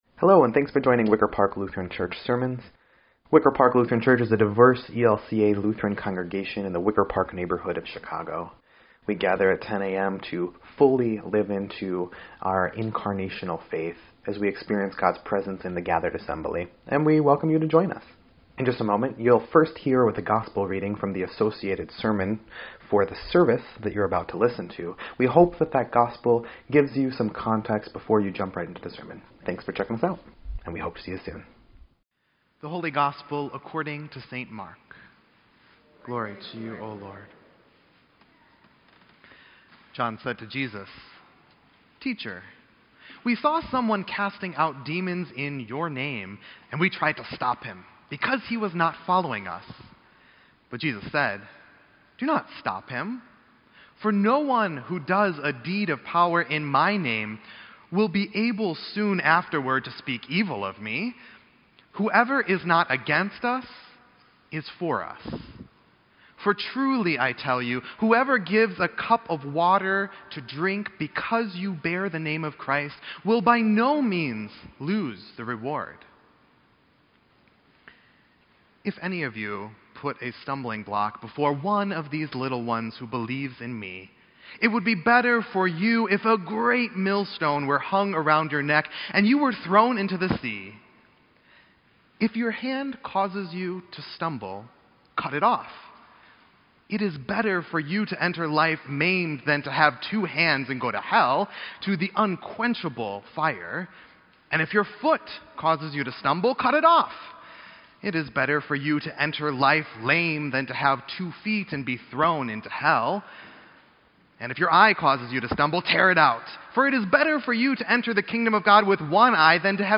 EDIT_Sermon_9_30_18.mp3